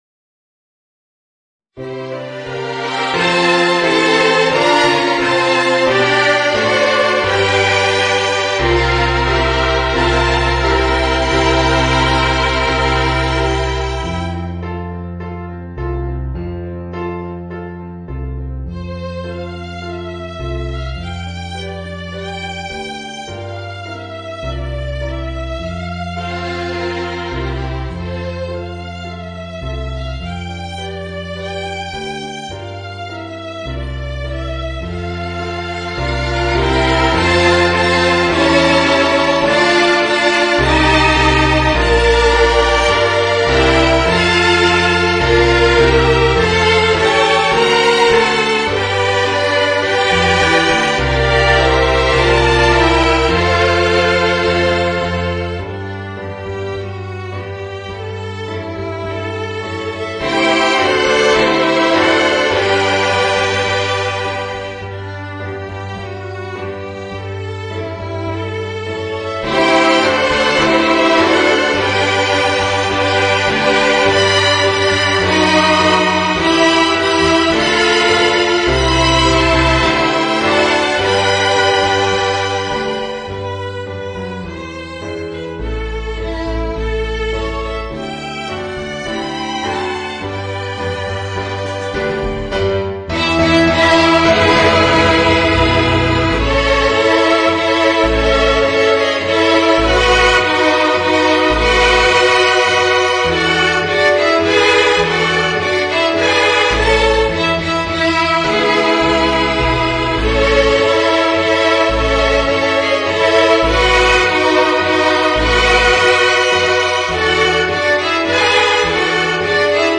(ヴァイオリン四重奏+ピアノ)